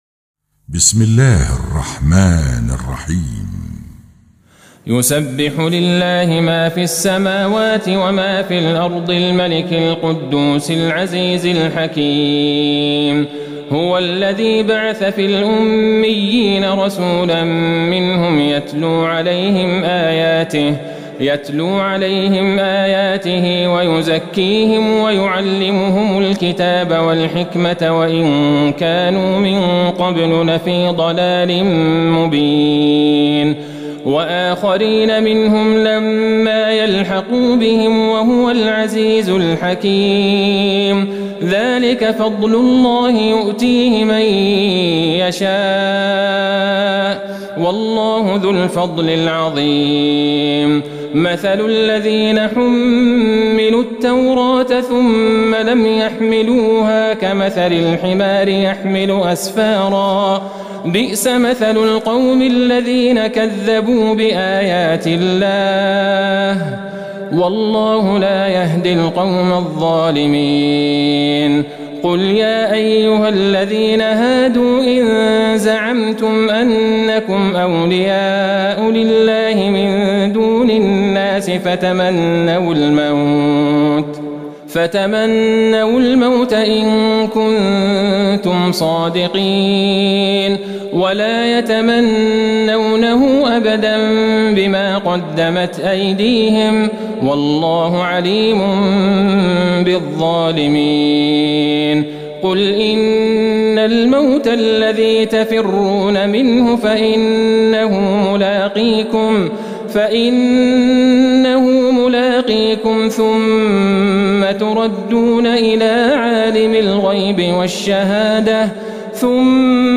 تهجد ٢٨ رمضان ١٤٤١هـ سورة الجمعة والمنافقون والتغابن والطلاق > تراويح الحرم النبوي عام 1441 🕌 > التراويح - تلاوات الحرمين